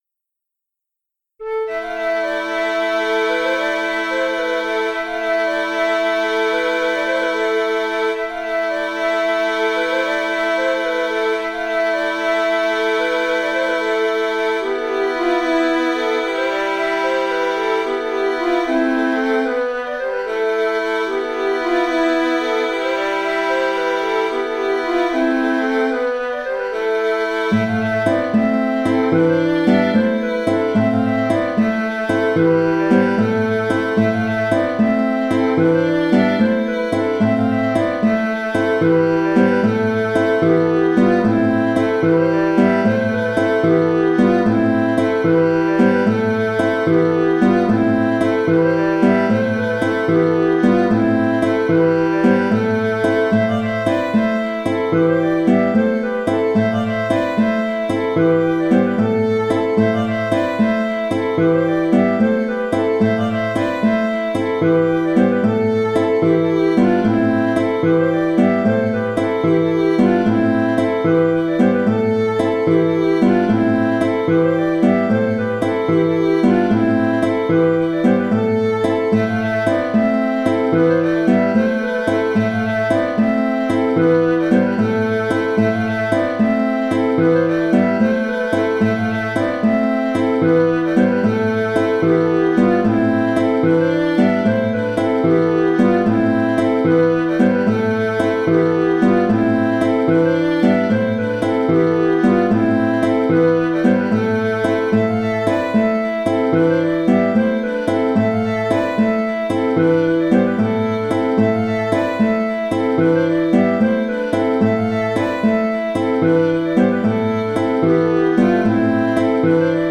Limousine (La) v1 (Bourrée ) - Musique folk
Bourrée 3 temps
Variations des instruments, variations des contre-chants (ce que je présente ici), et même variations des tonalités (ce que je ne présente pas ici).
En ce qui concerne les contrechants, j’en ai fait cinq que l’on peut enchaîner.
Attention, pour le dernier contre-chant, j’ai modifié deux accords (E7 au lieu de Em), pour changer un peu la couleur en fin de morceau.